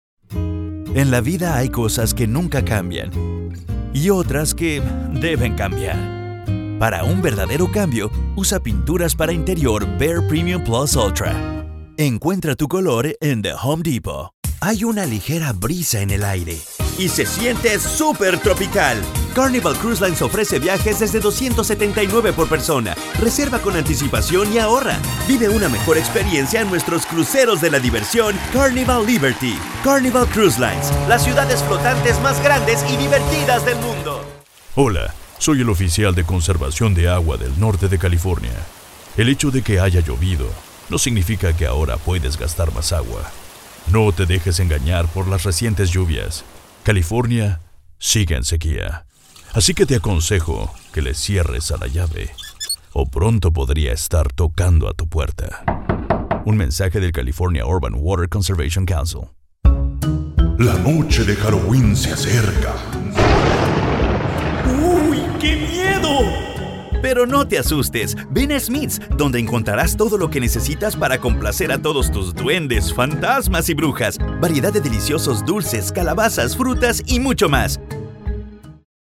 Latin American Spanish Voice, VO talent, Spanish Dubbing Actor, Locutor, Announcer, Hispanic Media Producer.
Sprechprobe: Werbung (Muttersprache):